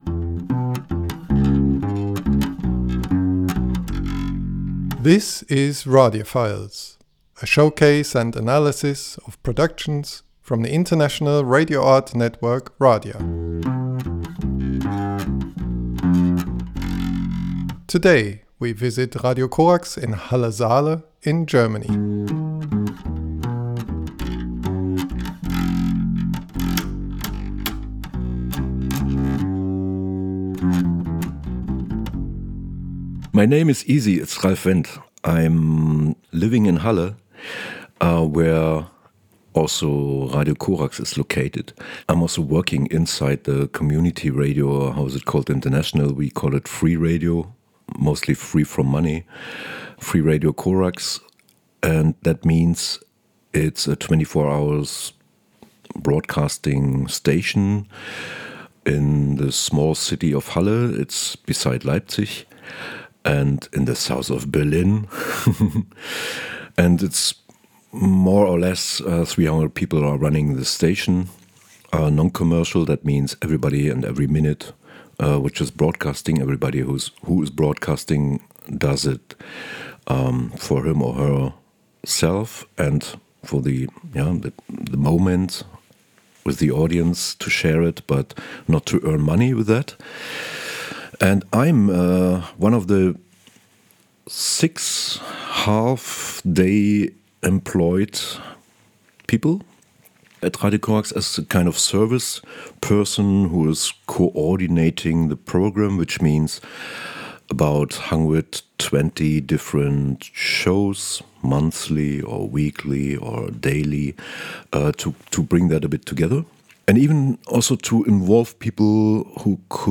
Radiaphiles: Radio Corax (Audio) Feb 10, 2018 shows Radiaphiles Produced by Mobile Radio. broadcasts Radio Corax : Feb 10, 2018: 11am - 12pm For this Radiaphiles broadcast, Mobile Radio speak...